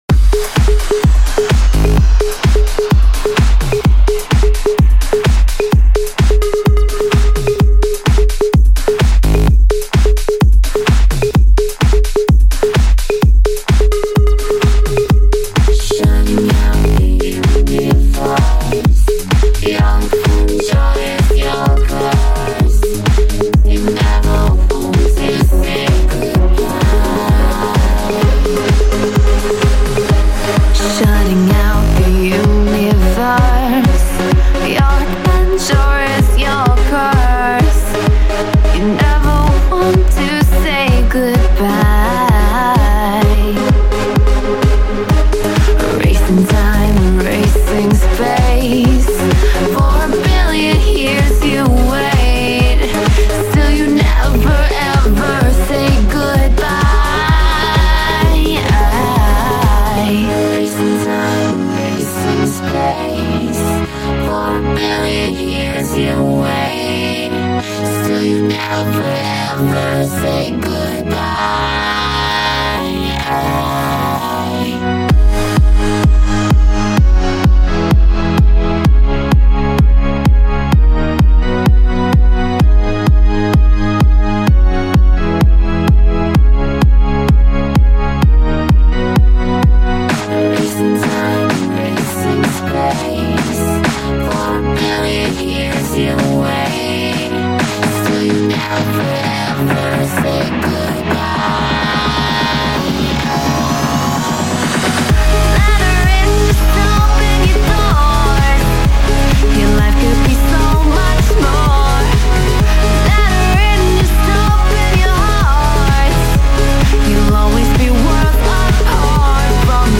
I made this on my livestream.
genre:remix